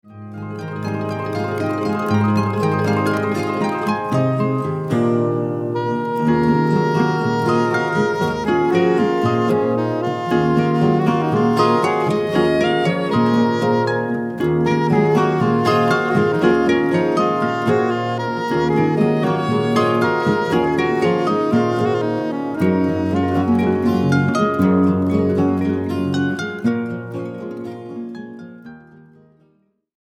percussion
drums